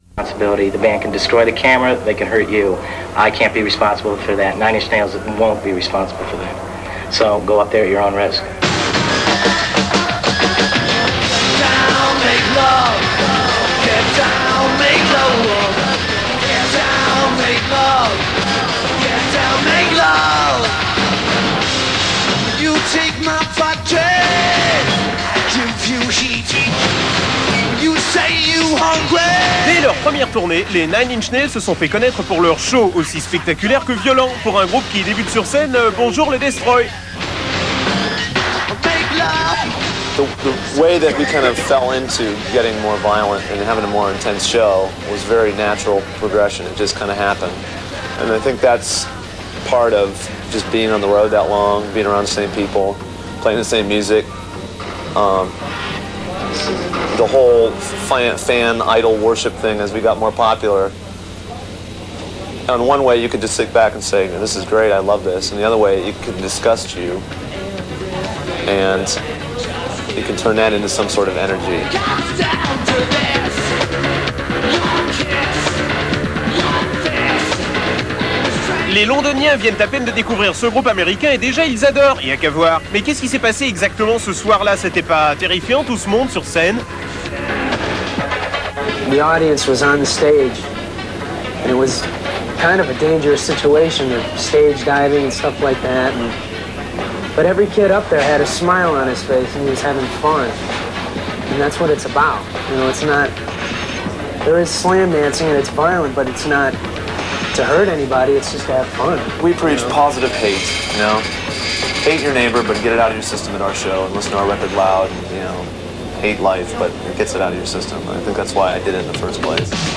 Recorded in London
NIN_FrInterview90.mp3